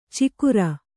♪ cikura